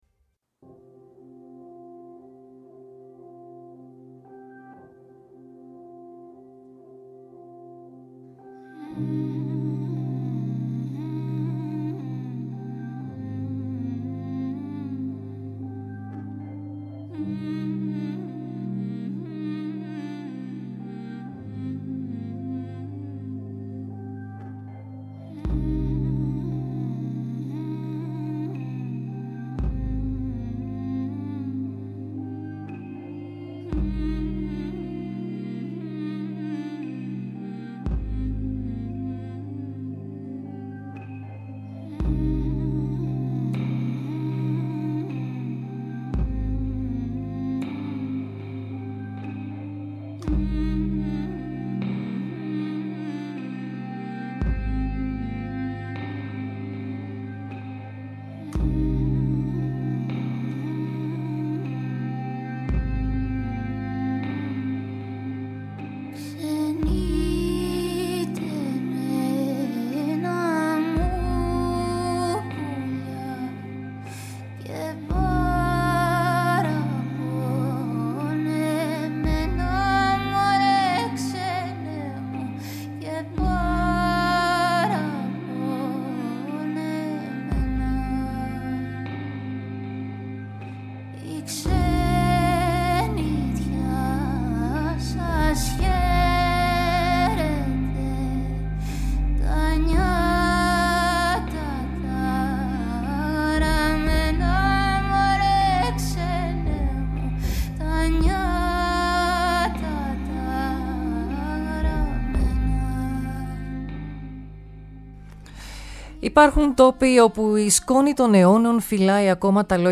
Με ηχοτοπία από κελαηδήματα, κλαρίνα και παραδοσιακά τραγούδια, οι ακροατές περπατούν νοερά στα καλντερίμια και στις καμαρωτές εξώπορτες του χωριού, γνωρίζοντας τους: